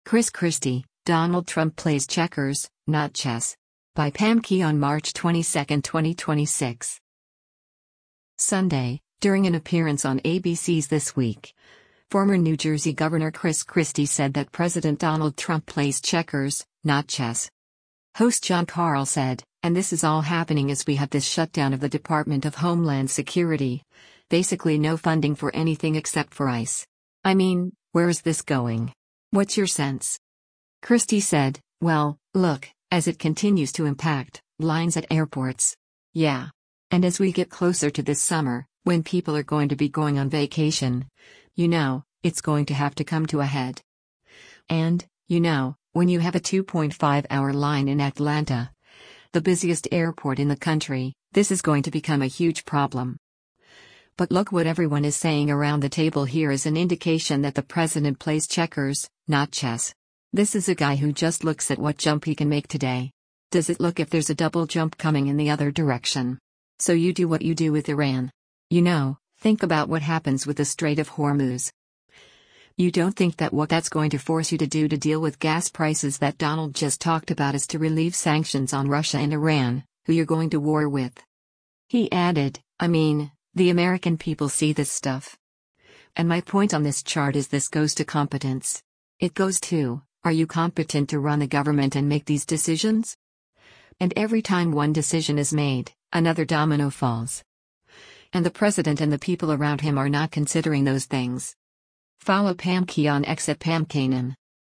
Sunday, during an appearance on ABC’s “This Week,” former New Jersey Gov. Chris Christie said that President Donald Trump “plays checkers, not chess.”